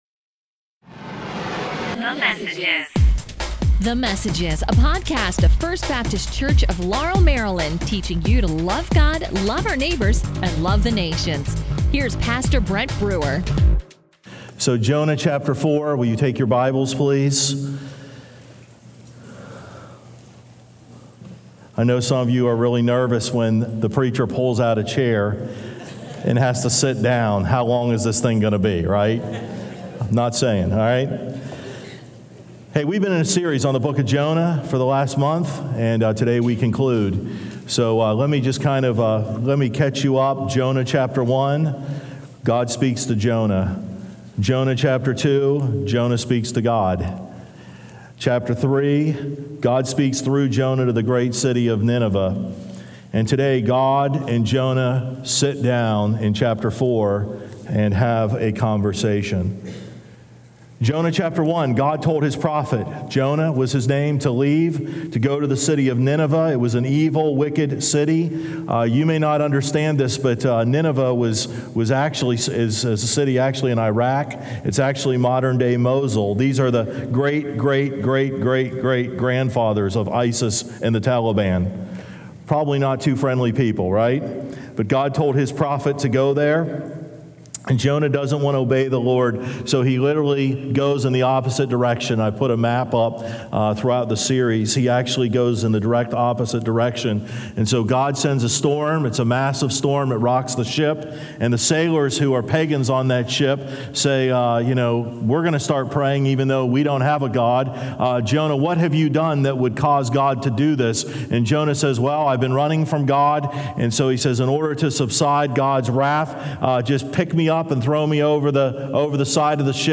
A message from the series "Abraham."